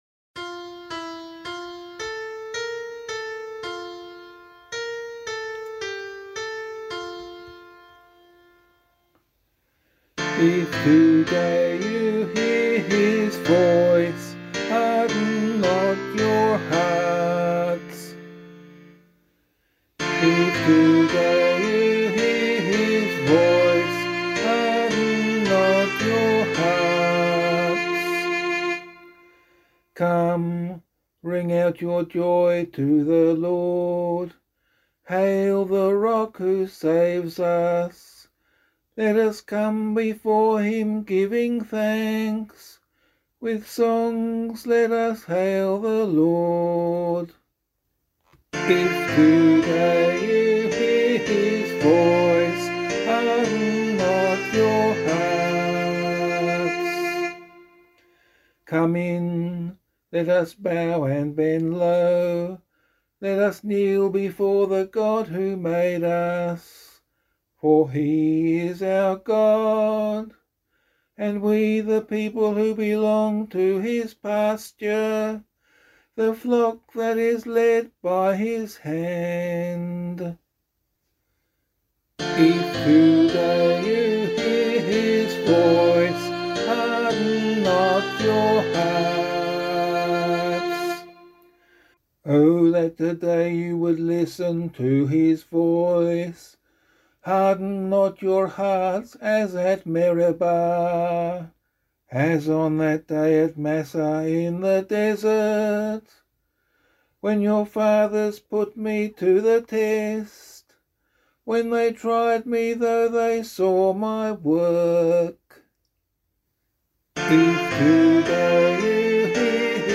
015 Lent 3 Psalm A [LiturgyShare 1 - Oz] - vocal.mp3